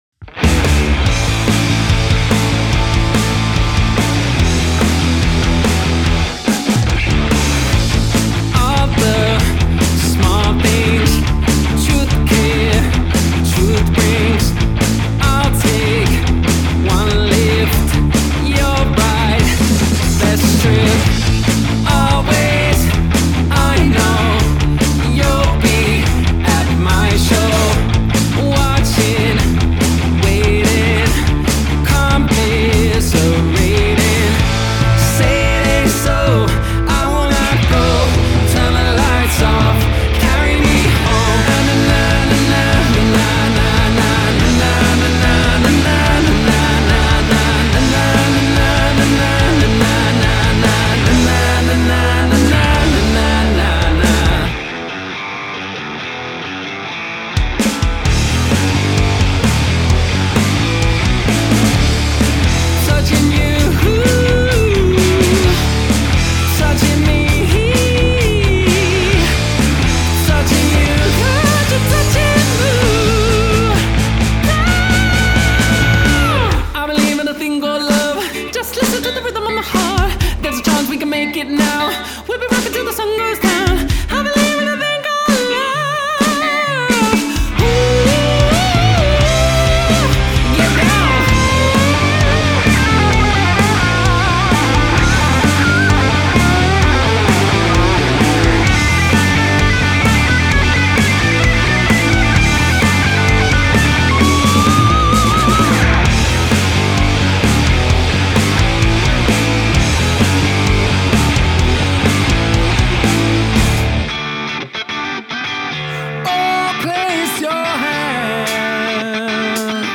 • Three-part harmonies for a huge live sound!
Vocals / Guitar / Synth, Guitar, Bass, Drums